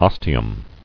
[os·ti·um]